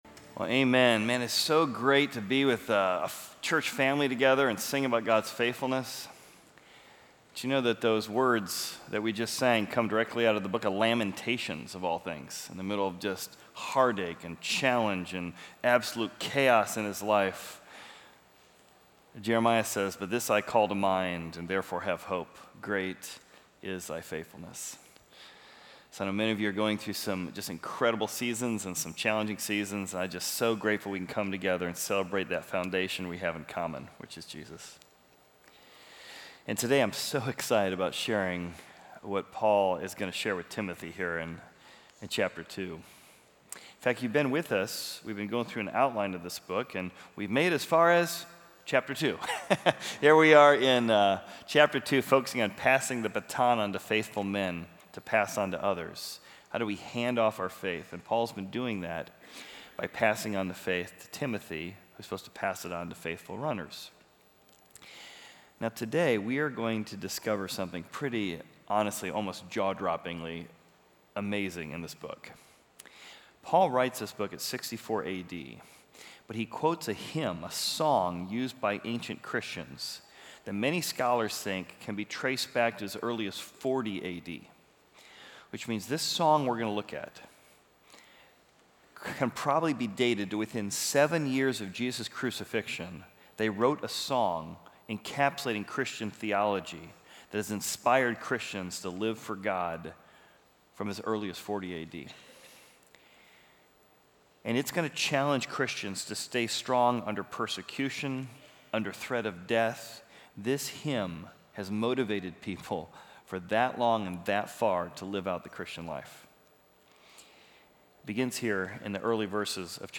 Equipping Service / 2 Timothy: Pass It On / Present Yourself Approved